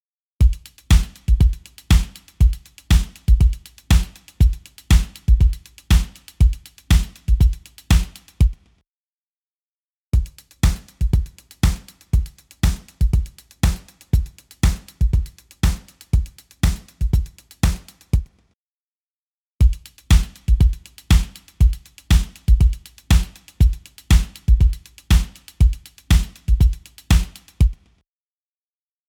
EQ45 | Drum Machine | Preset: More Boom For Your Buck